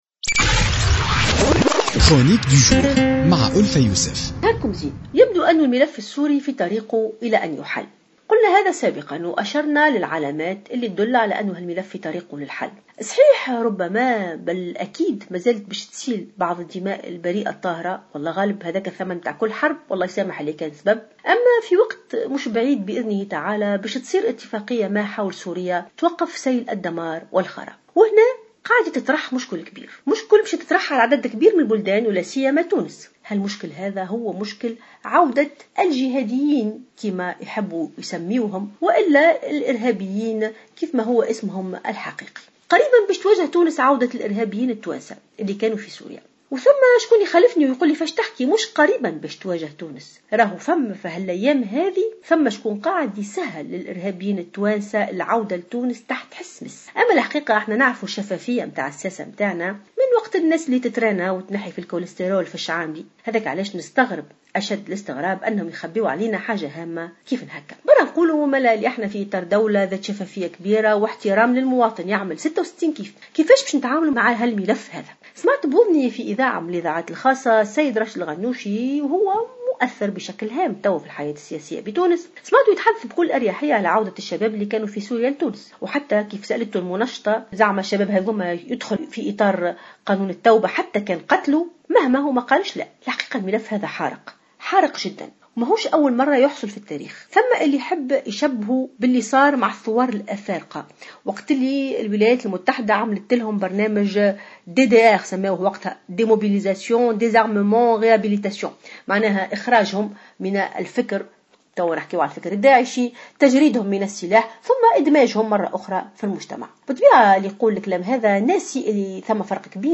تطرقت الكاتبة ألفة يوسف في افتتاحية اليوم الجمعة 29 جويلية 2016 إلى الملف السوري الذي اعتبرت أنه في طريقه إلى الحل استنادا الى عدة علامات دالة على ذلك.